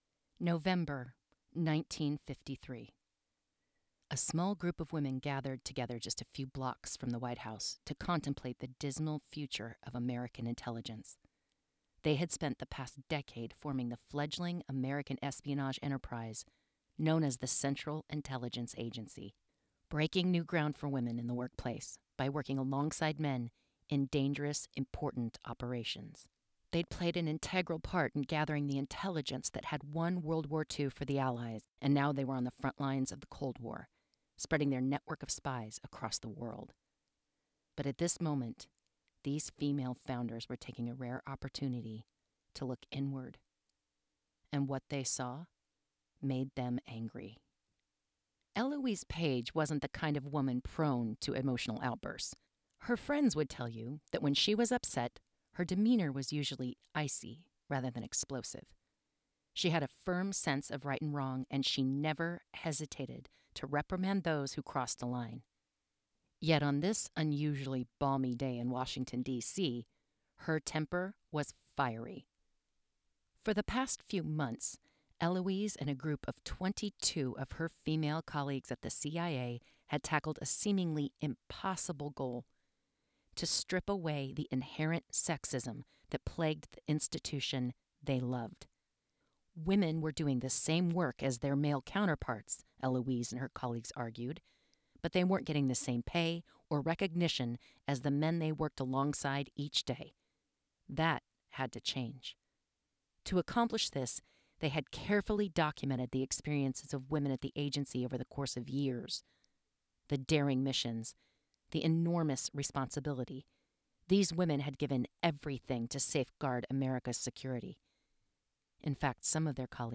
Non-Fiction, History
3RD PERSON
Home Recording Studio:
• PD70 Dynamic Broadcast Mic
Non-Fiction-Historical-3rd-Person-Women-of-the-CIA.wav